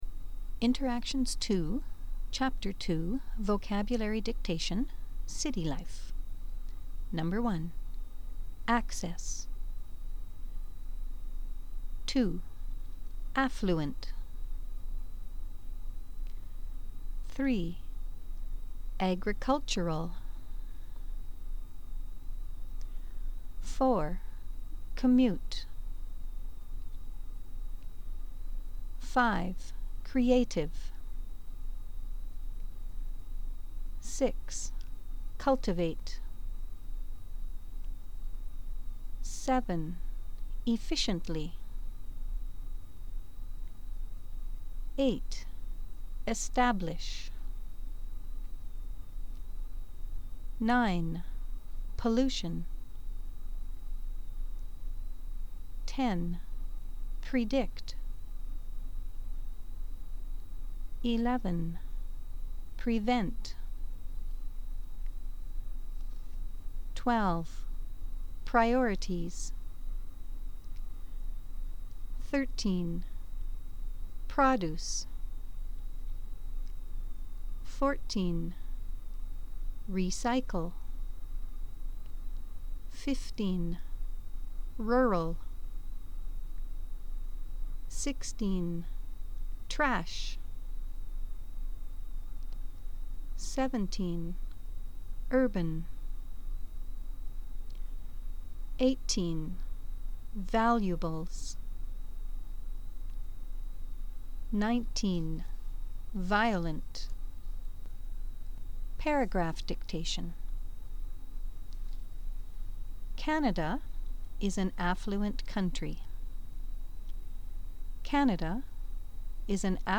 Dictations
First you will hear a list of words.  Then you will hear a paragraph dictation.